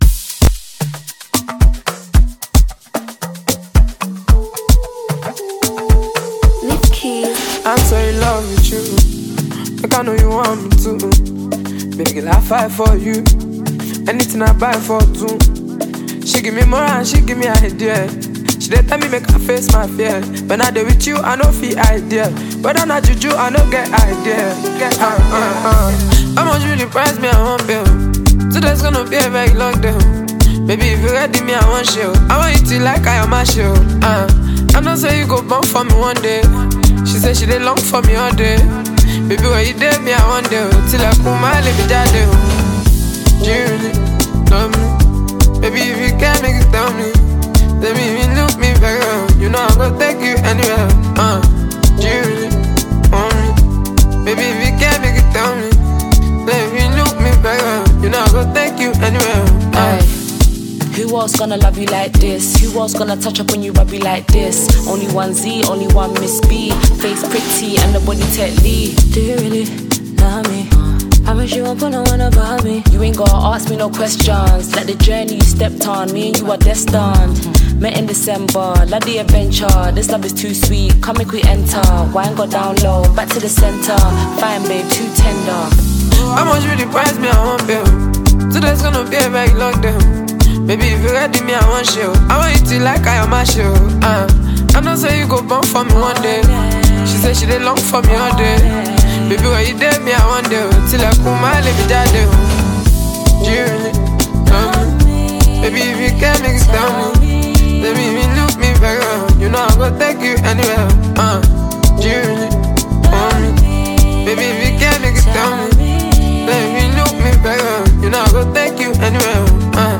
a notable female rap virtuoso and poet